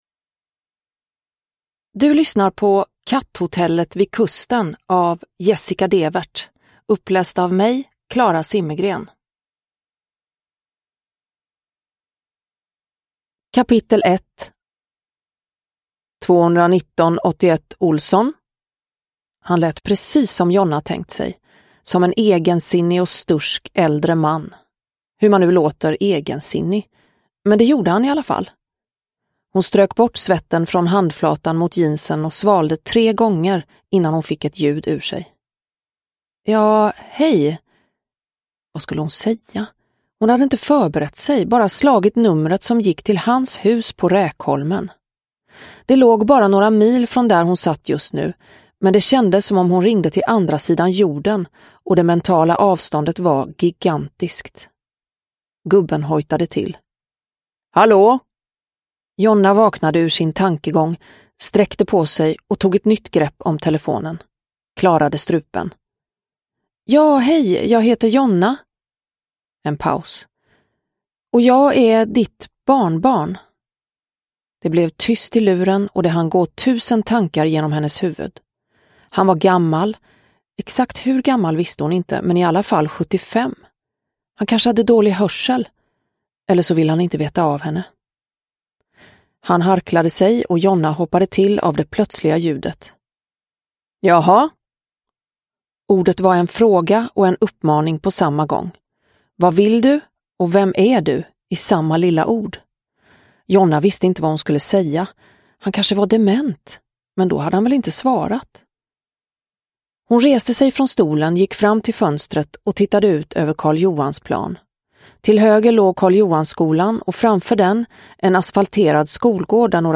Katthotellet vid kusten – Ljudbok – Laddas ner
Uppläsare: Klara Zimmergren